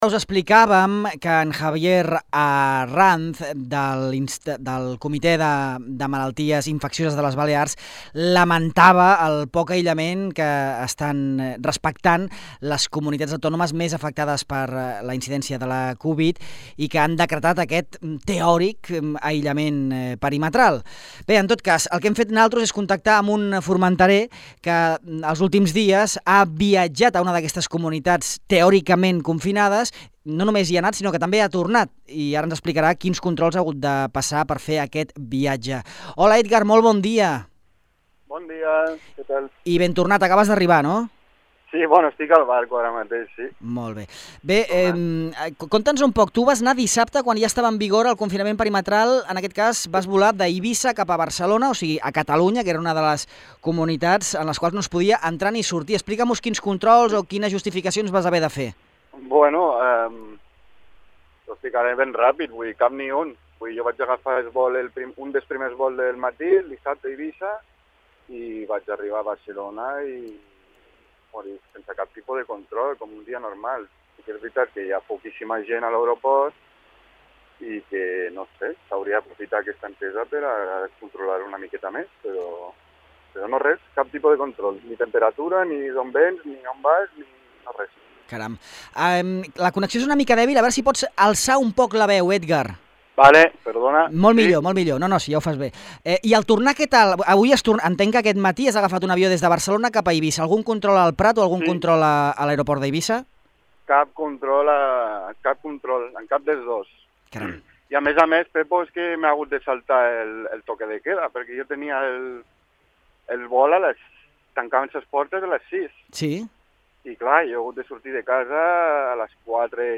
Un resident a Formentera ha explicat a Ràdio Illa que el passat dissabte viatjà en avió des d’Eivissa cap a Barcelona i aquest matí ha tornat des de l’aeroport del Prat-Josep Tarradellas a les Pitiüses.